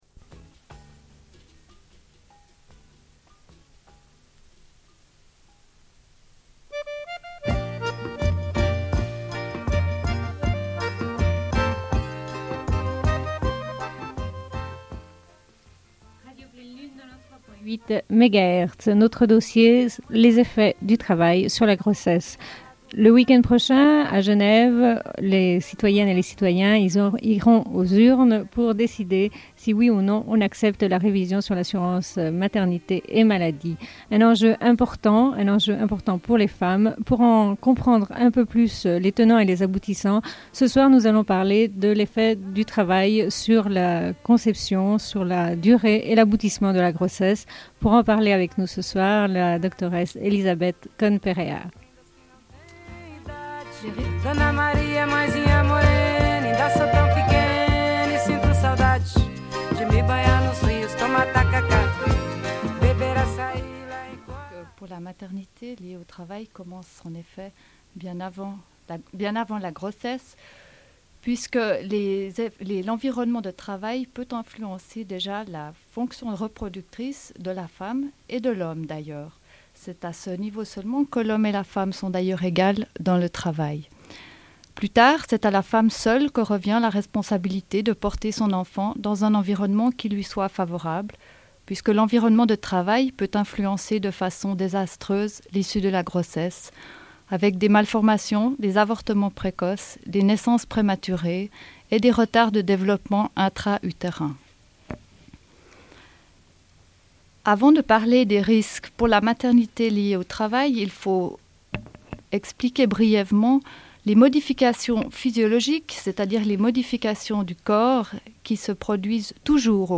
Une cassette audio, face A31:36
00:00:18 // Présentation de l'émission et début de l'entretien.